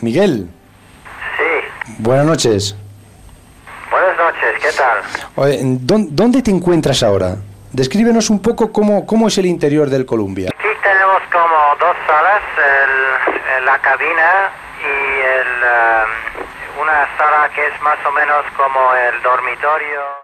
Entrevista a l'astronauta Michael López Alegría que està a la nau Columbia.